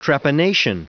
Prononciation du mot trepanation en anglais (fichier audio)